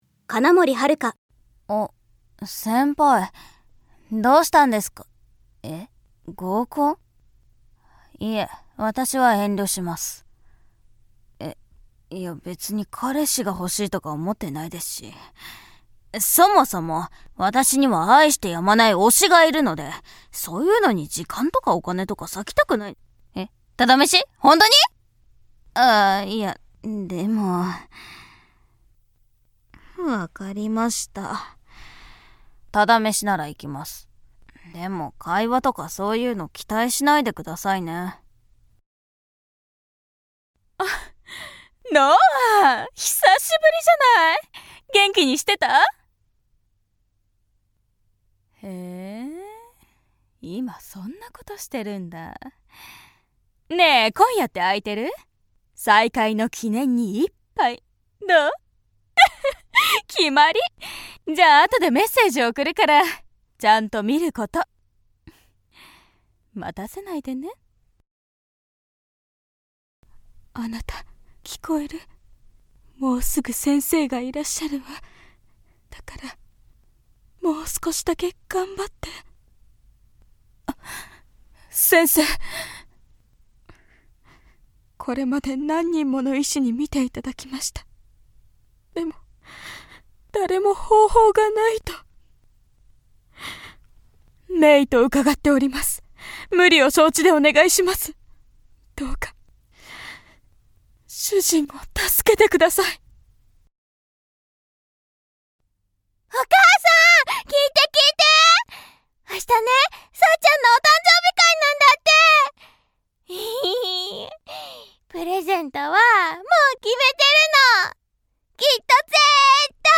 ◆台詞